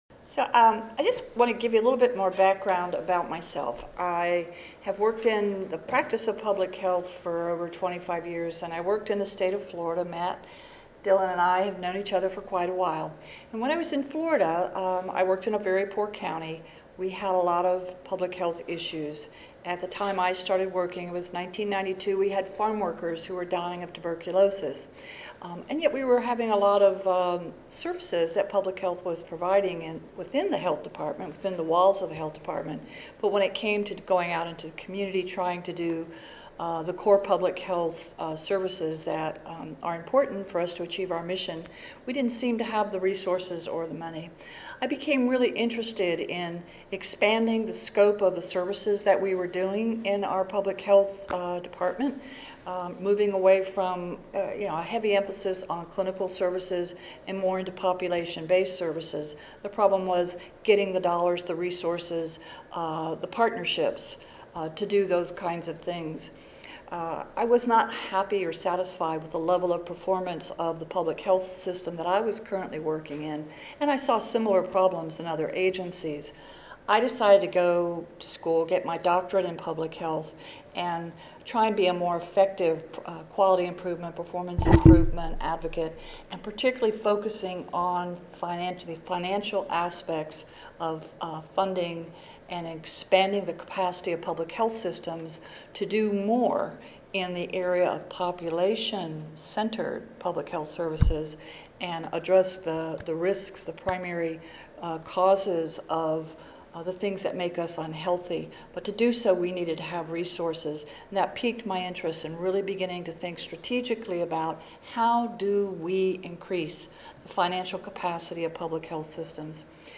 This session introduces attendees to a set of financial ratios designed to: � Track changes in financial condition; � Identify emerging financial problems in time to take action; � Identify existing problems that are not obvious to the agency's staff; � Develop solutions to deal with identified problems; � Project future funding needs; and � Present a clear image of the agency's strengths and weaknesses for use with key stakeholders Learning Objectives: 1.